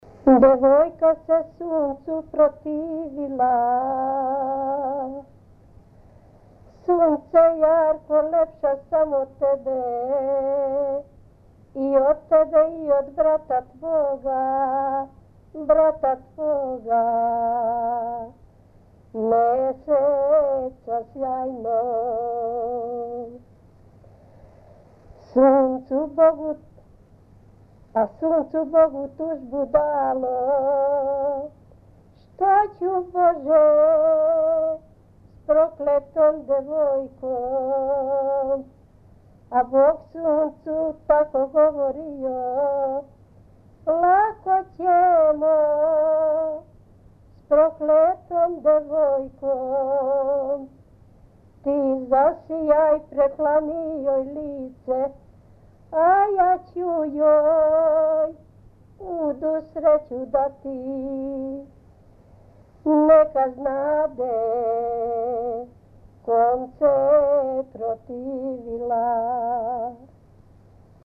Тема: Епске песме, приповедне песме, романсе, баладе
Место: Чип (снимљено у Пештсентлеринцу)
Напомена: Приповедна песма, мелодијски модел као и у претходним нумерама (064, 065).